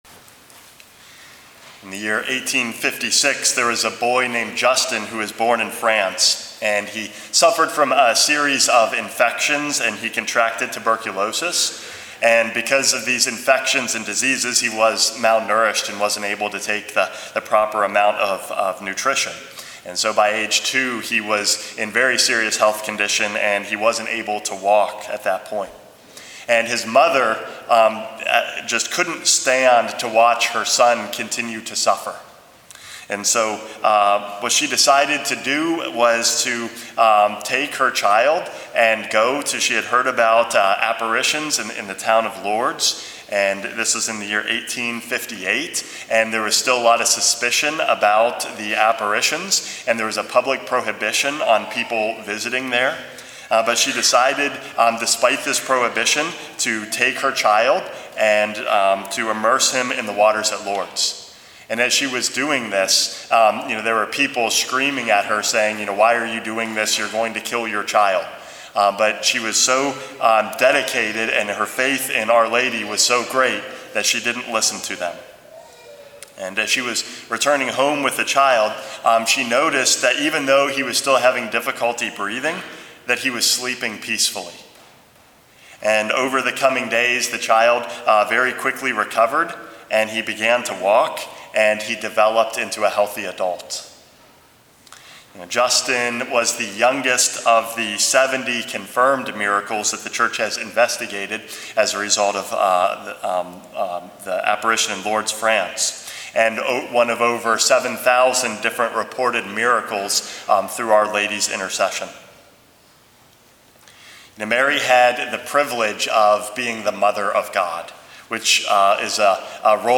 Homily #433 - Mother of God and Mother of the Church